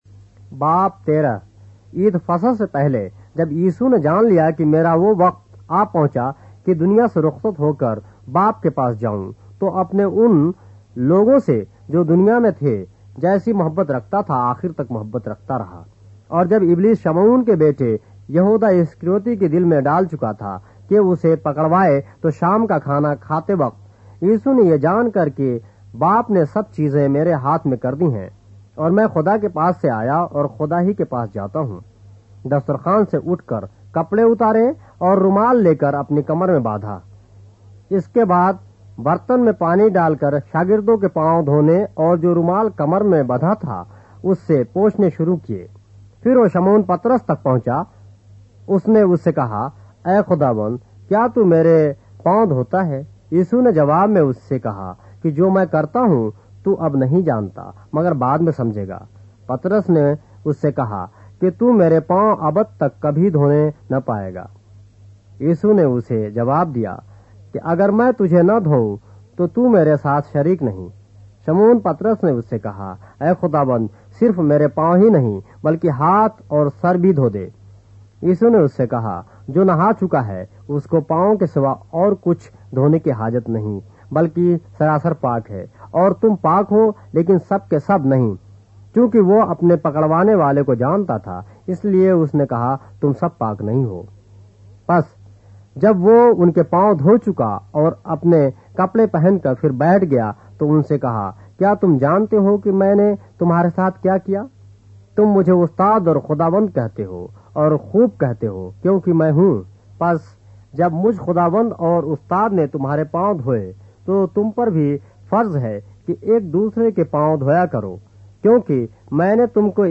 اردو بائبل کے باب - آڈیو روایت کے ساتھ - John, chapter 13 of the Holy Bible in Urdu